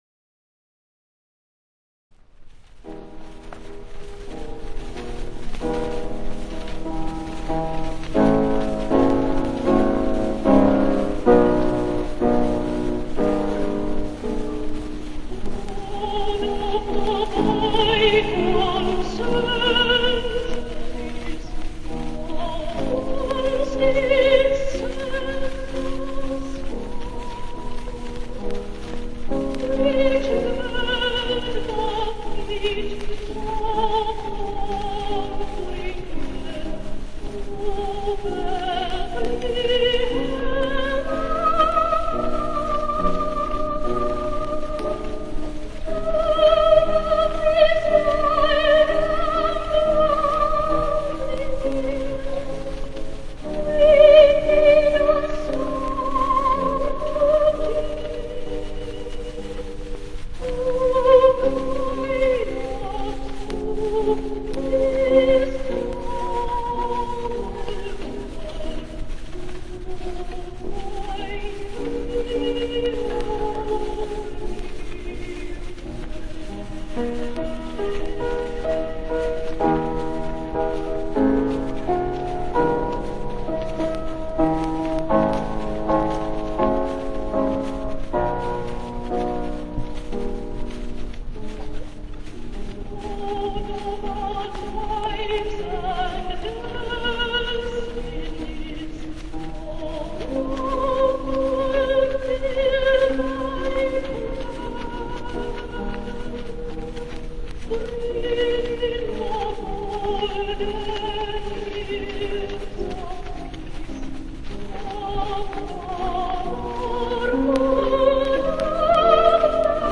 Rec. live at the Royal Albert Hall
Direct Disc Recording